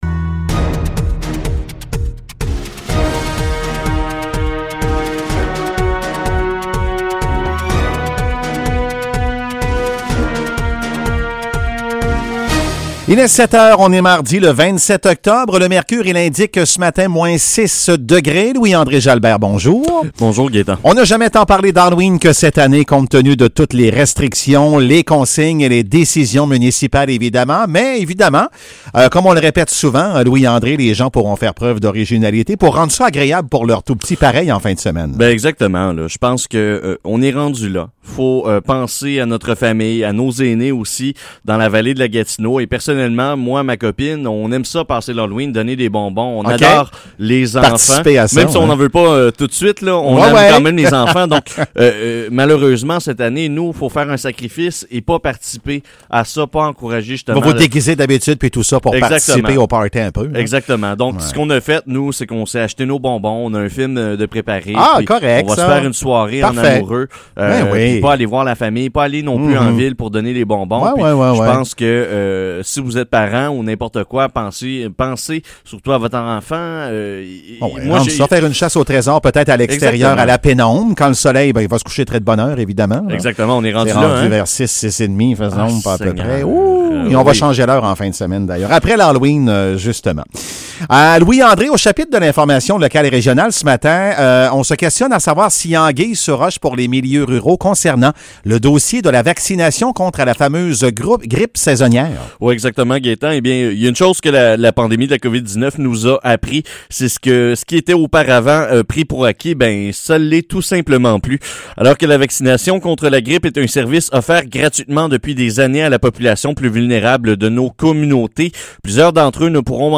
Nouvelles locales - 27 octobre 2020 - 7 h